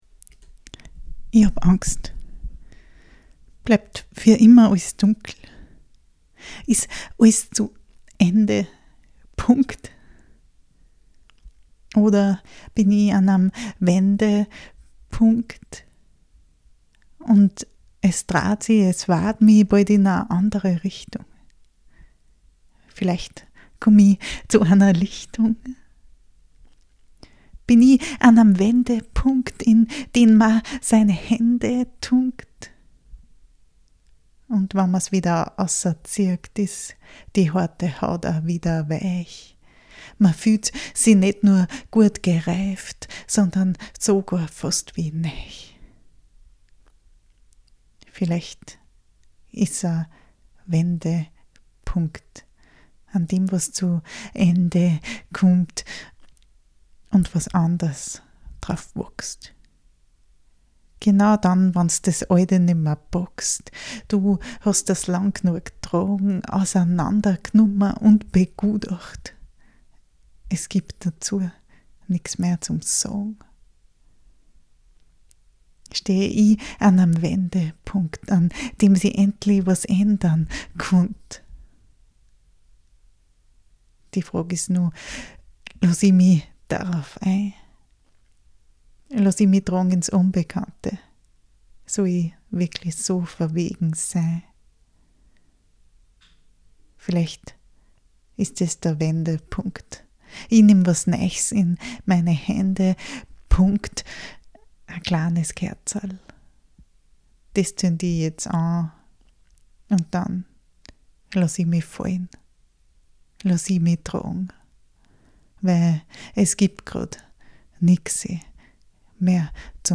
Wie das klingt?